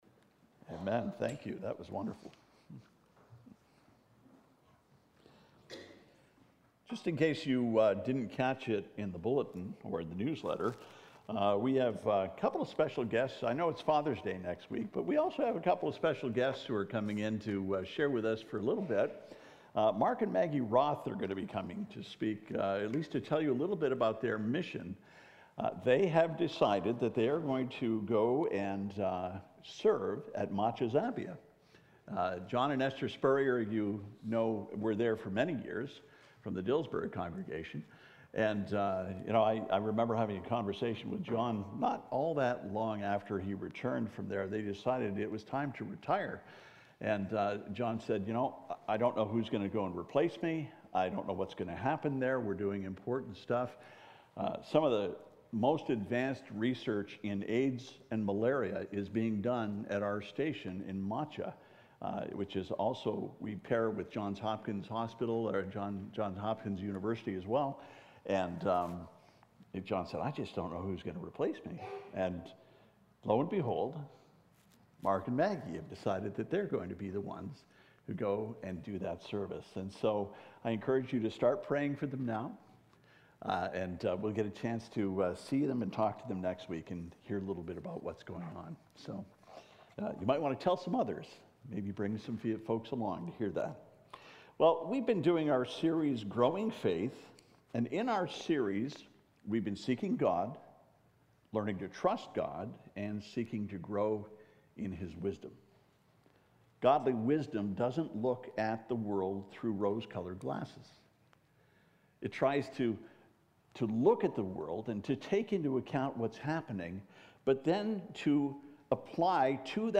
Growing Faith Pt 8: “The Fruit of Suffering” James 5:7-12 « FABIC Sermons